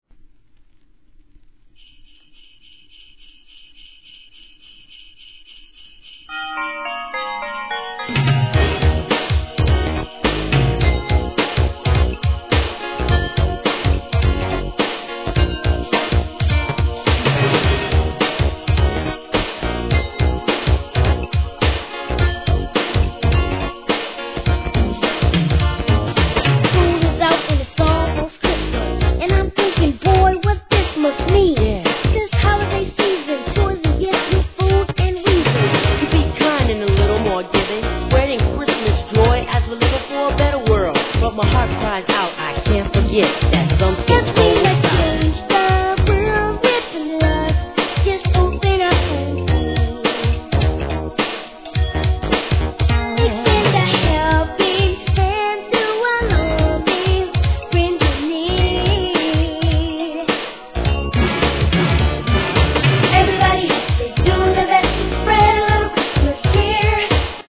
¥ 1,320 税込 関連カテゴリ SOUL/FUNK/etc...